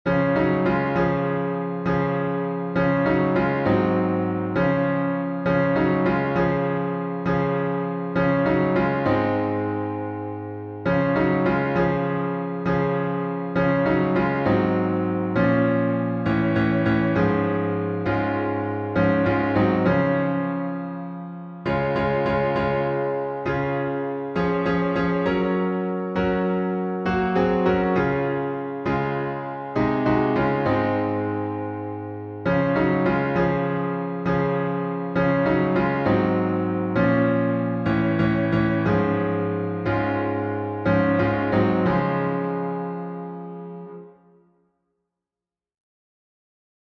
#5095: I Must Tell Jesus — D flat major | Mobile Hymns
I_Must_Tell_Jesus_Dflat.mp3